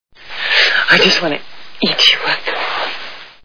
The Fly Movie Sound Bites